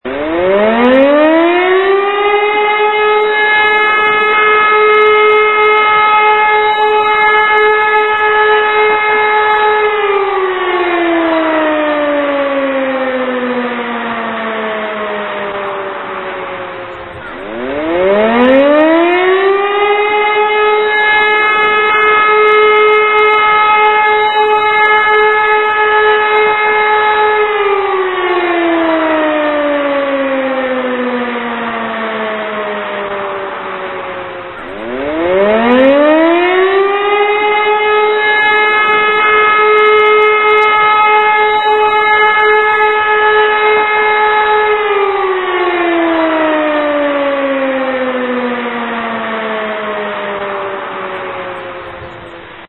Freiwillige Feuerwehr Hochneukirchen - Sirenenalarme
Diese Warnungen und Alarmierungen erfolgen über Sirenensignale und über die Medien.
feuerwehreinsatz.mp3